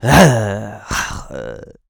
Male_High_Roar_01.wav